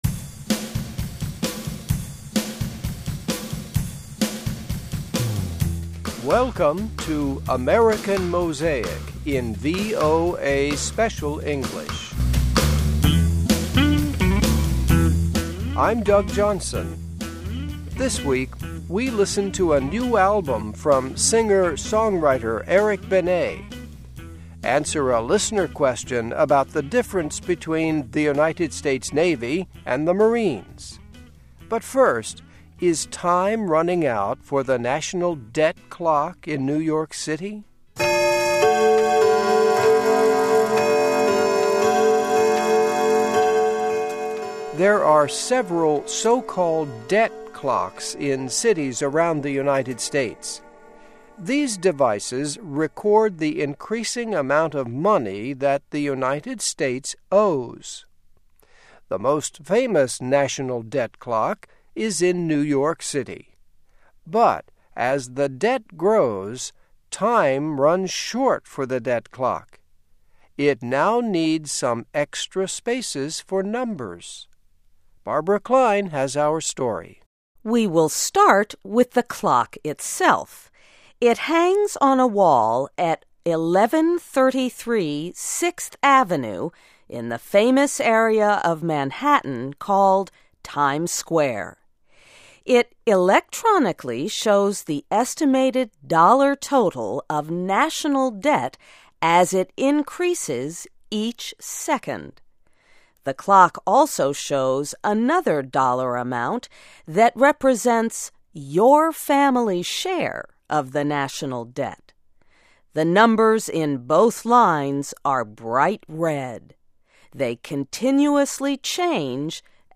Listen and Read Along - Text with Audio - For ESL Students - For Learning English
Welcome to AMERICAN MOSAIC in VOA Special English.